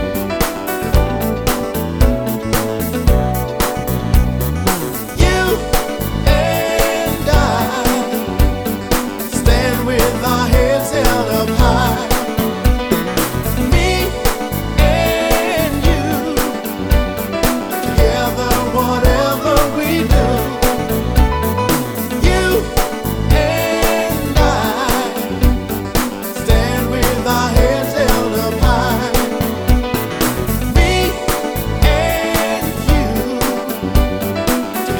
Жанр: Рок / R&B / Танцевальные / Соул